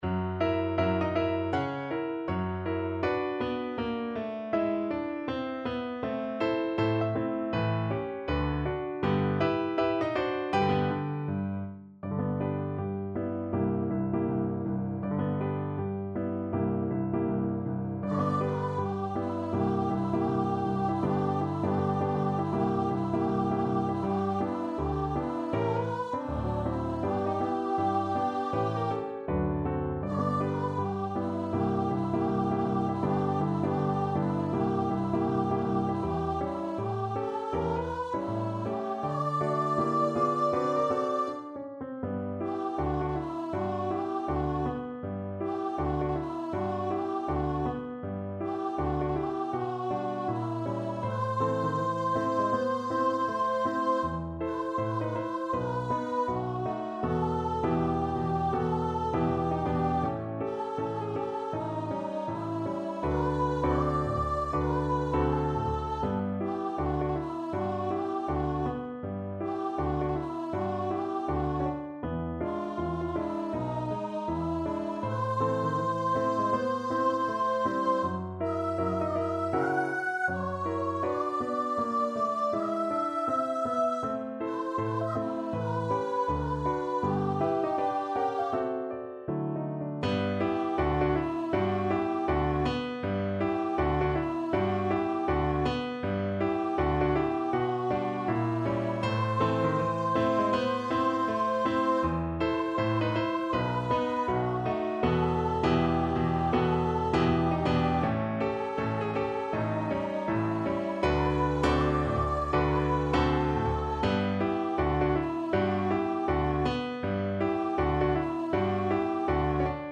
~ = 160 Moderato
Jazz (View more Jazz Voice Music)